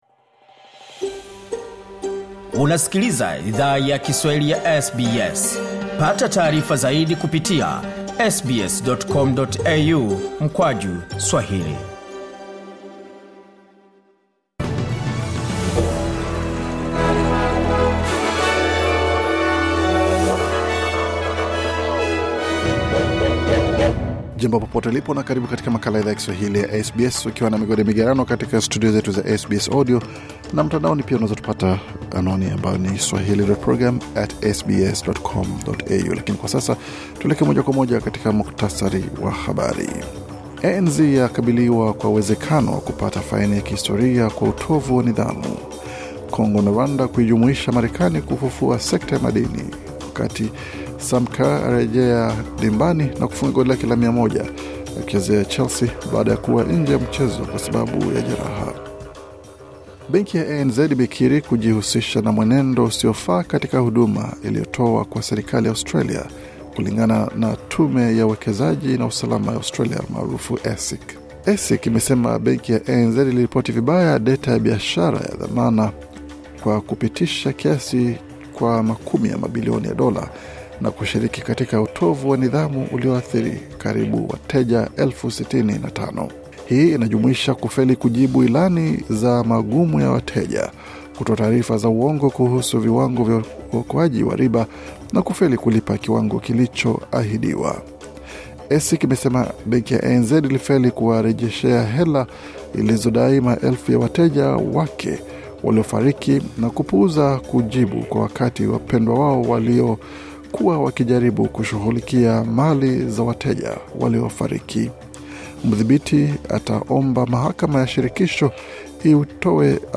Taarifa ya Habari 15 Septemba 2025